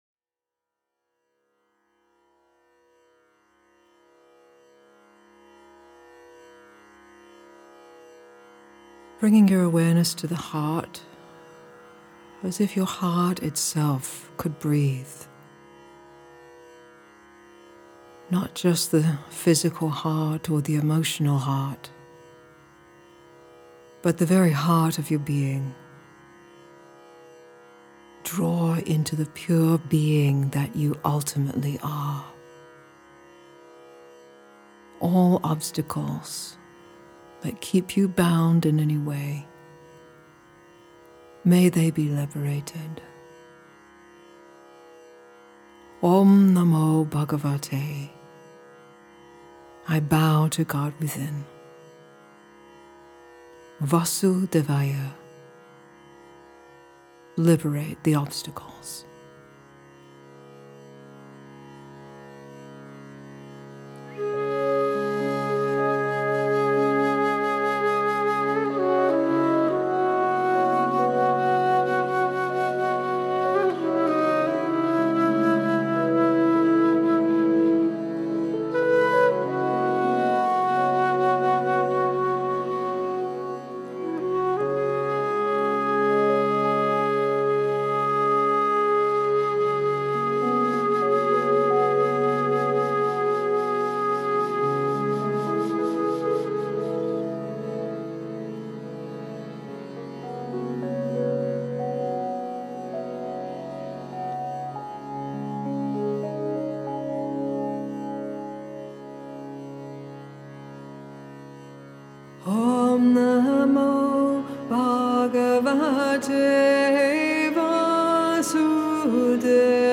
Backing Vocals
Lead vocals & harmonium
electric guitar
flute & keyboard
Tabla
percussion & bass